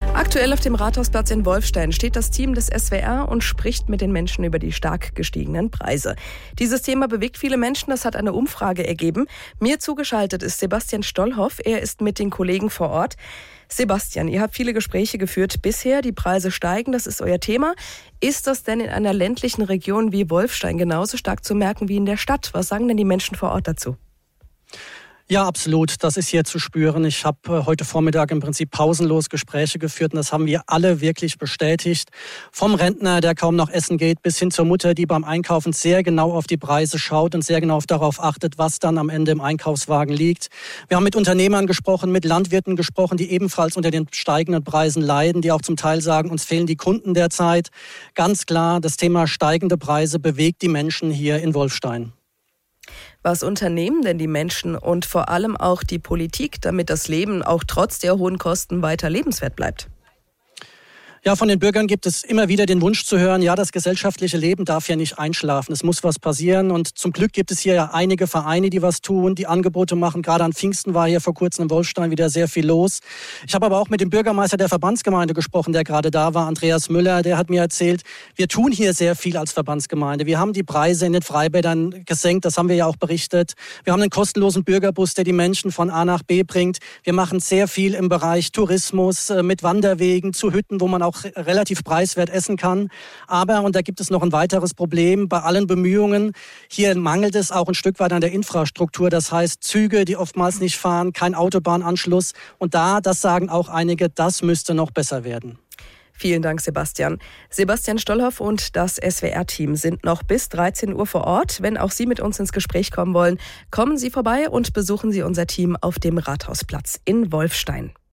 Der SWR berichtete live aus Wolfstein
Außerdem wurde am Vormittag auch live aus Wolfstein in den SWR4 Regionalnachrichten Kaiserslautern berichtet.